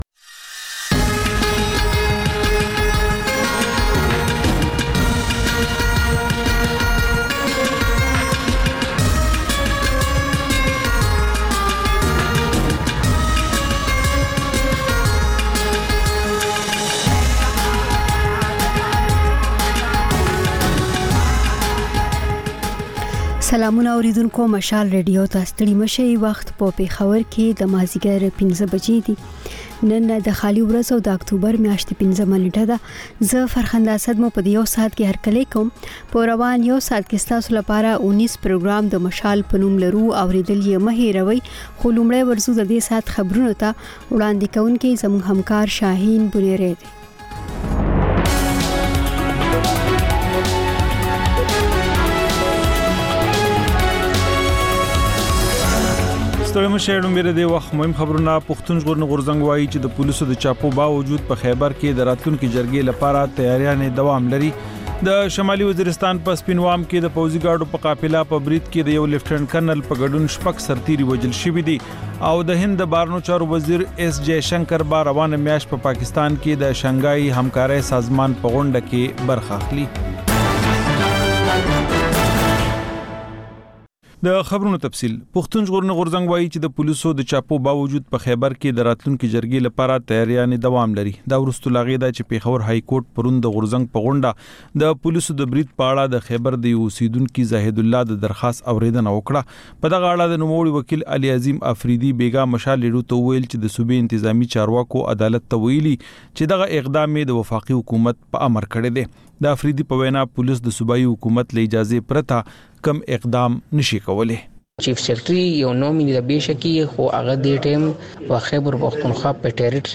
د مشال راډیو ماښامنۍ خپرونه. د خپرونې پیل له خبرونو کېږي، بیا ورپسې رپورټونه خپرېږي.
ځینې ورځې دا ماښامنۍ خپرونه مو یوې ژوندۍ اوونیزې خپرونې ته ځانګړې کړې وي چې تر خبرونو سمدستي وروسته خپرېږي.